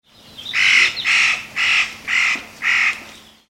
hoshigarasu_c1.mp3